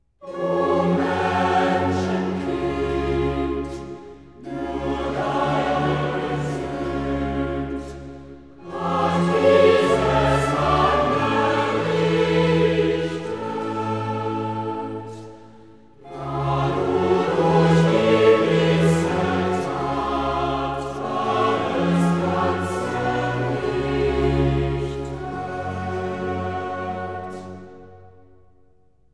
Coro